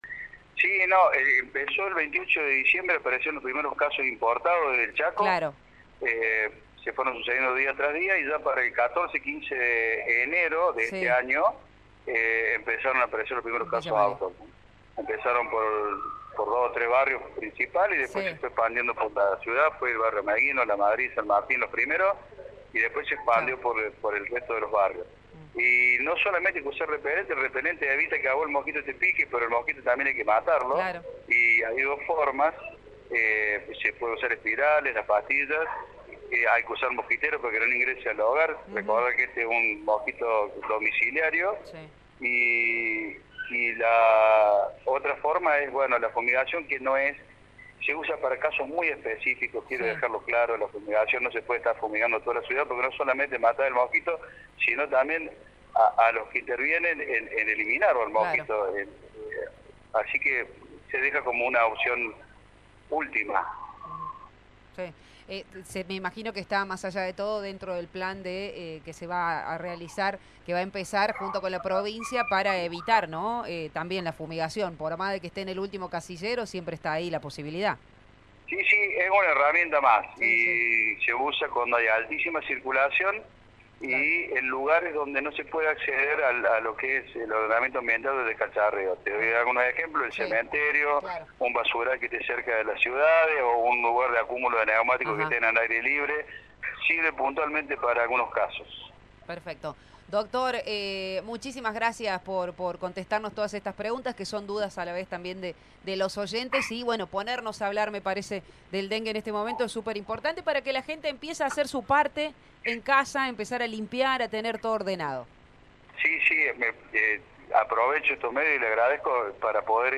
El secretario de Salud, César Rivera dialogó con Radio Show sobre qué medidas tomar para ser más responsables y conscientes a la hora de cuidarse.